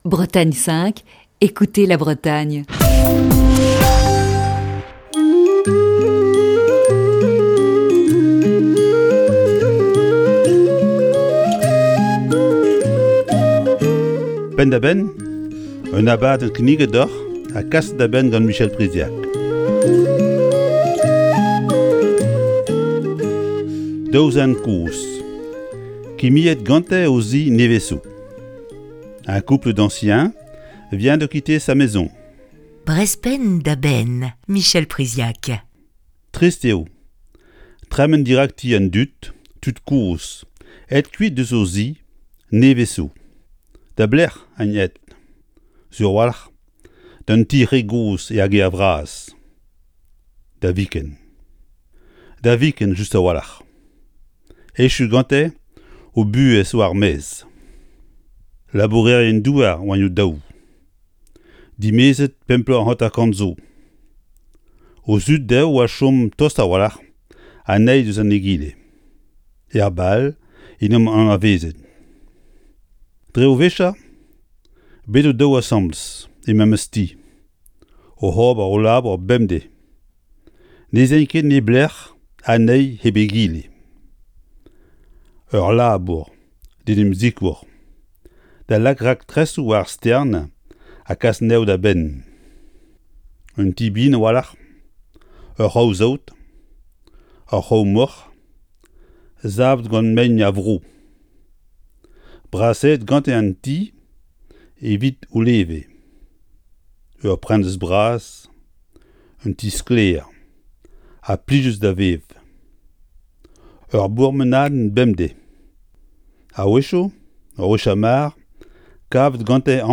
Chronique du 8 juin 2020. Les volets de la maison sont fermés, l'herbe pousse dans les allées et les plantes ne sont plus arrosées.